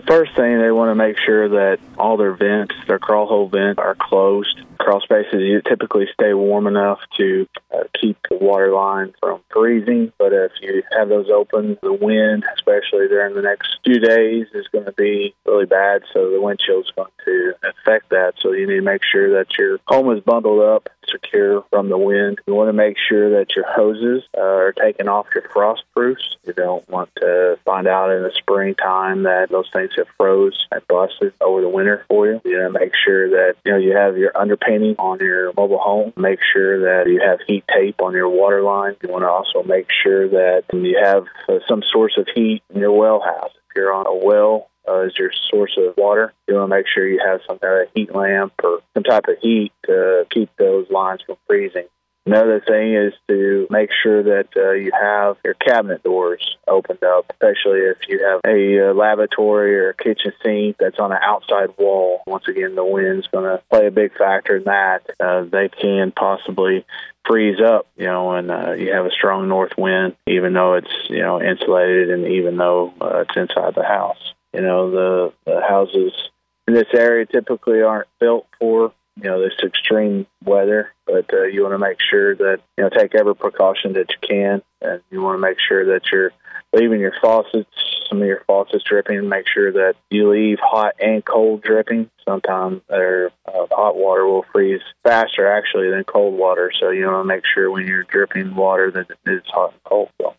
Local plumber shares tips for frigid temperatures